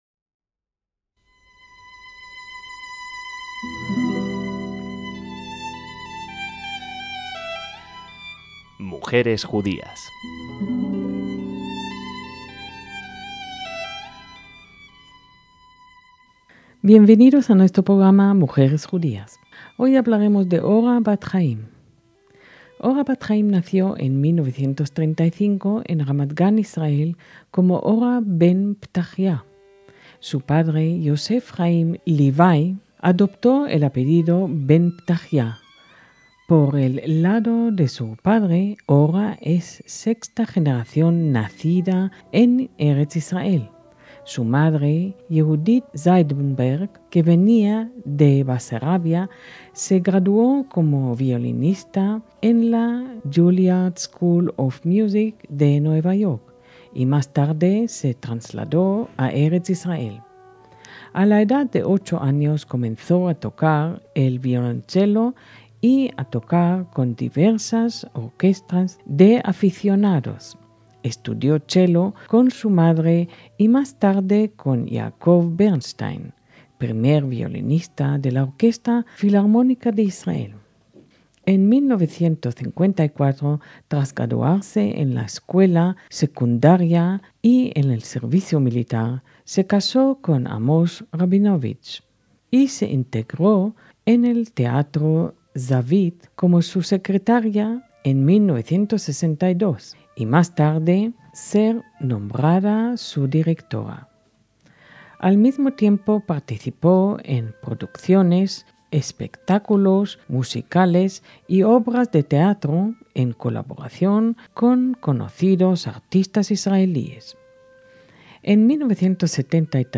Escuchamos algunas de ellas con su marido como solista.